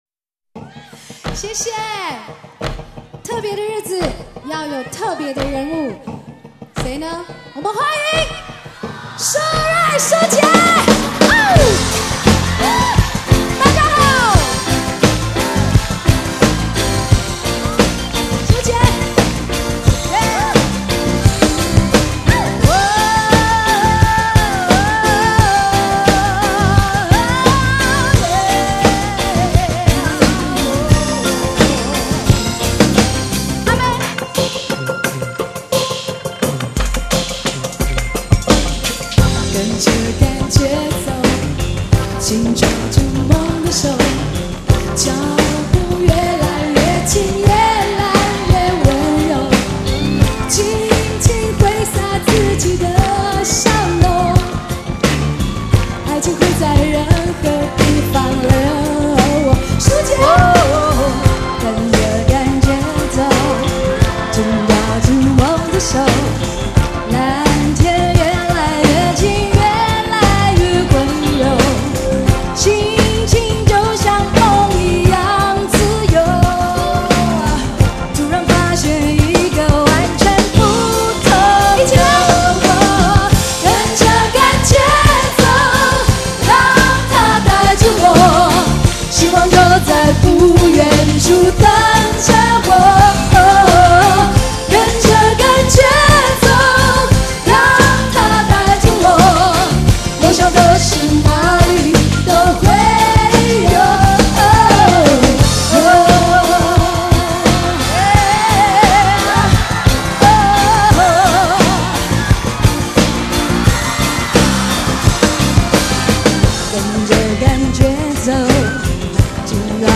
低音质试听